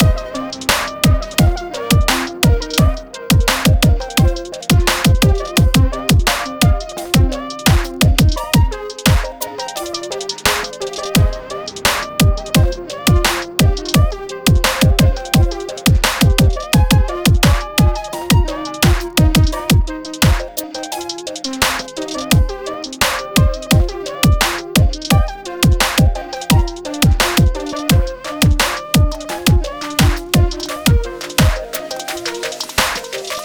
C Minor